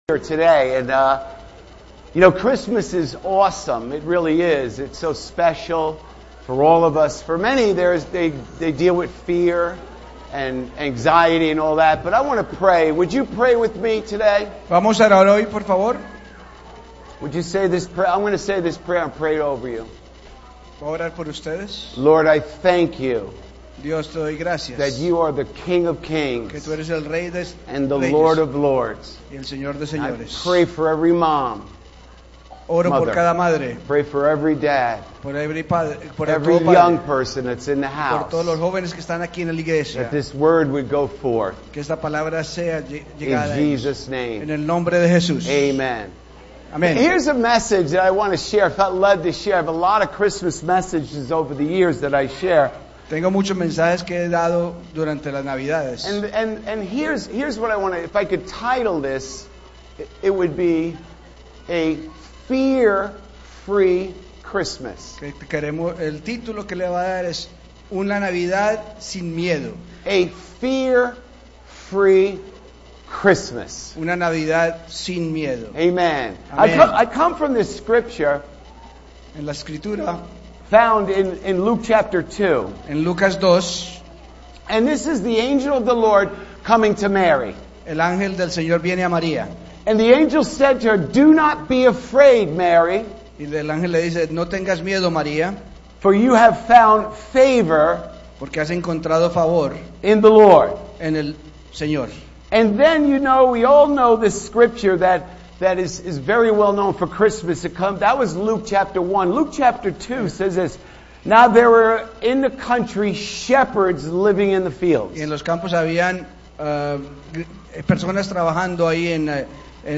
Sermons_2024 - Full Gospel Christian Church Of Copiague